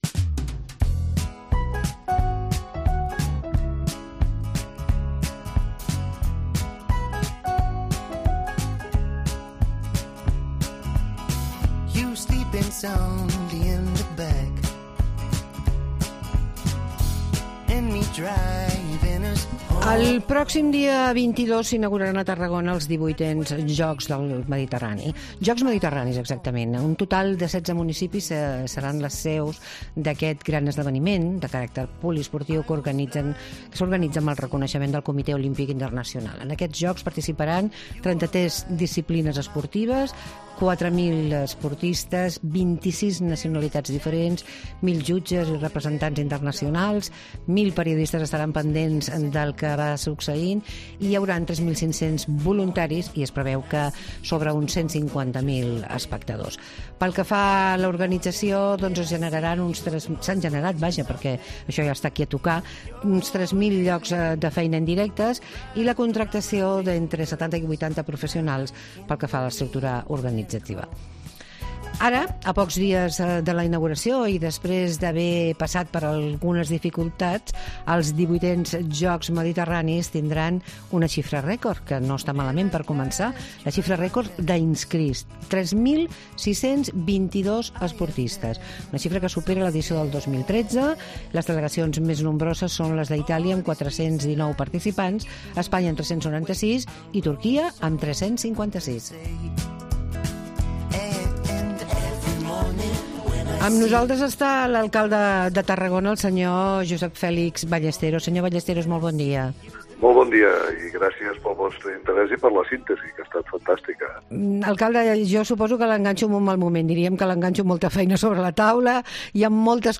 El 22 de juny començen els XVIII Jocs Mediterranis a Tarragona. Parlem amb l'alcalde de la ciutat, Josep Fèlix Ballesteros